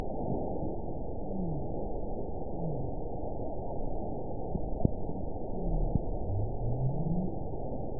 event 922087 date 12/26/24 time 09:38:49 GMT (5 months, 3 weeks ago) score 9.47 location TSS-AB04 detected by nrw target species NRW annotations +NRW Spectrogram: Frequency (kHz) vs. Time (s) audio not available .wav